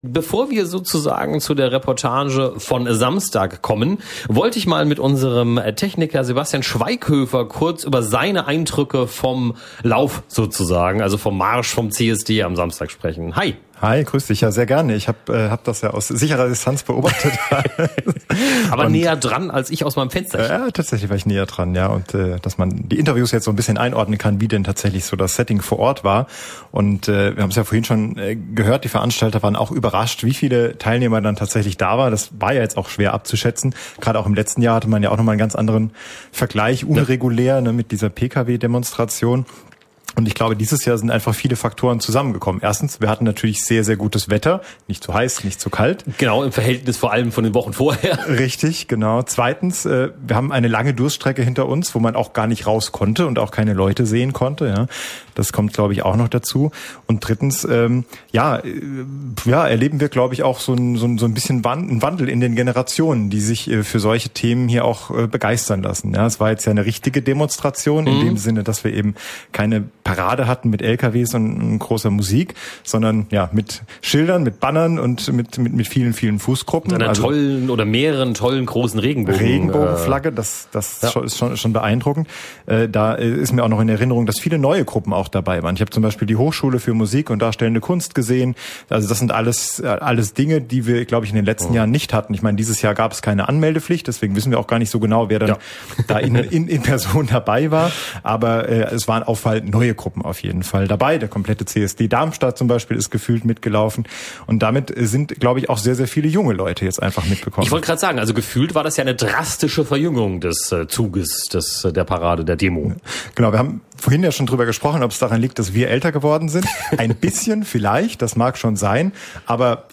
Die Livereportage auf radioX